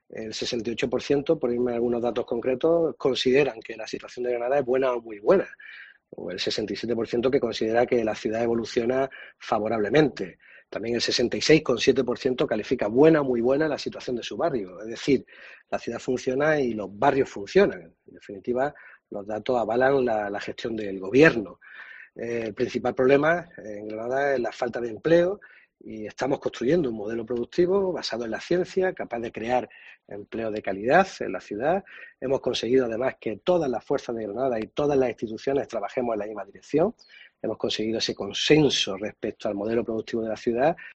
Jacobo Calvo, portavoz del equipo de gobierno
En un audio de valoración remitido a los medios, Calvo ha enfatizado que "no se comprende que esta encuesta encargada por la Junta de Andalucía haya hecho esa estimación" de ediles porque "a la pregunta de si mañana se celebrasen elecciones, el 23 por ciento lo haría al PSOE y el 23 por ciento lo haría al PP, así que algo no cuadra".